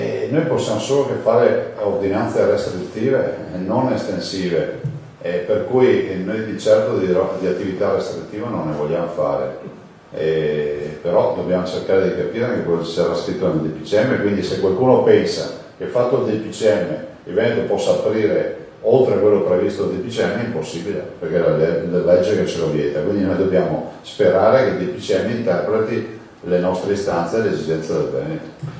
SUD CONTRO NORD, TAMPONI NELLE CASE DI RIPOSO, IL 4 MAGGIO…DALLA CONFERENZA STAMPA DI ZAIA